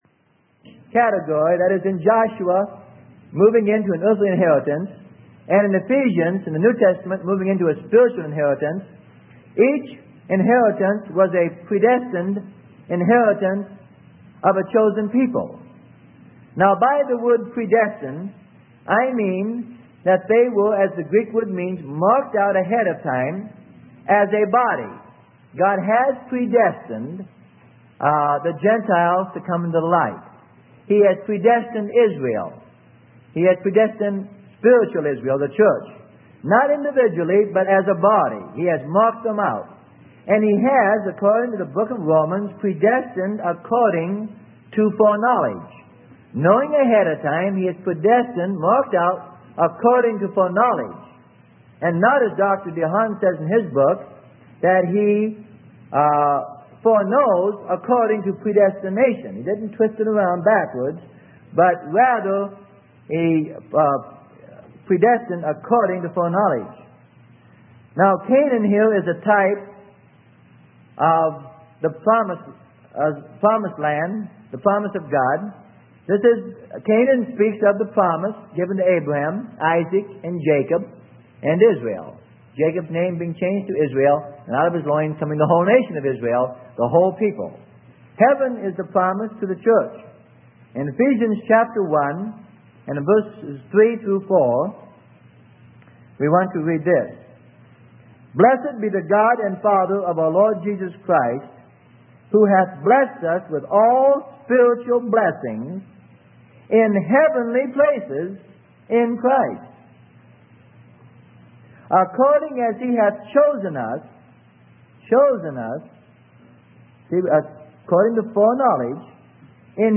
Sermon: Enter Overcome and Occupy - Part 03 - Freely Given Online Library